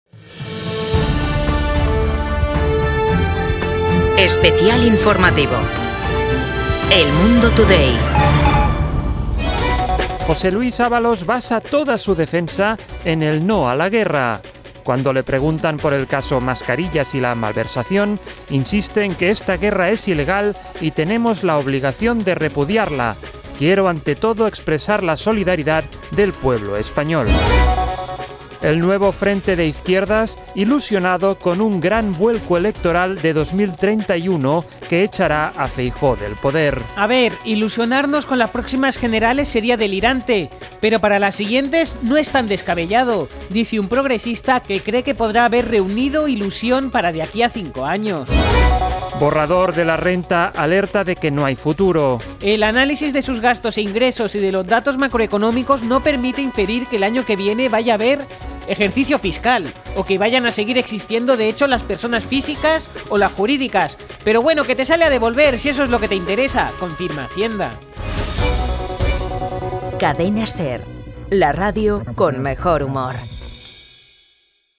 Boletín informativo de El Mundo Today | 3:00 AM